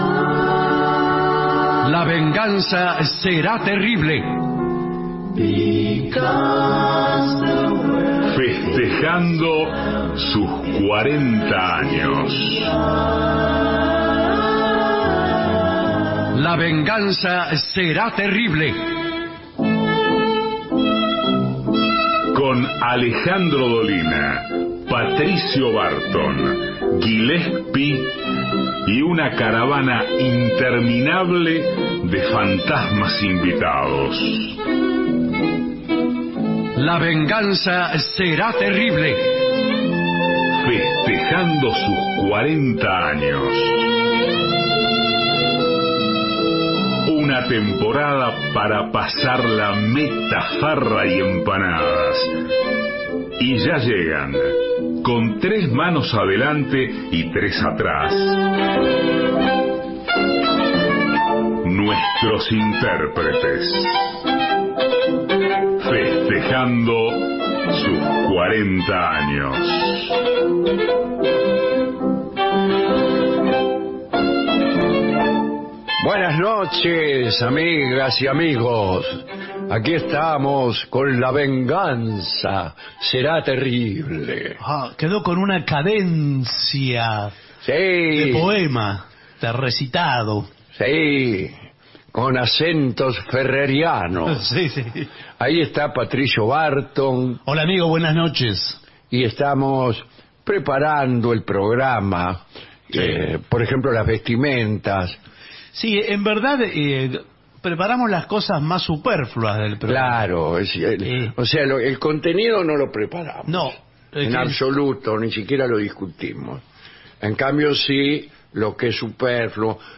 La Venganza Será Terrible: todo el año festejando los 40 años Estudios AM 750 Alejandro Dolina